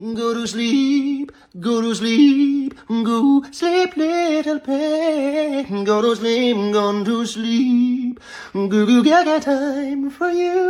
Go To Sleep meme sound effect
Go-To-Sleep-meme-sound-effect.mp3